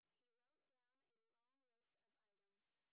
sp11_white_snr30.wav